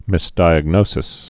(mĭs-dīəg-nōsĭs)